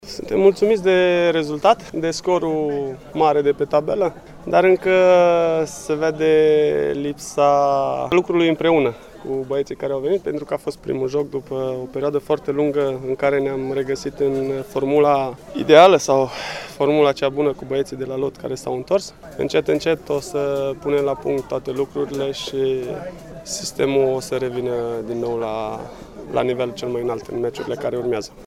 Declaraţii la final de meci: